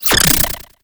Cri de Théffroi dans Pokémon HOME.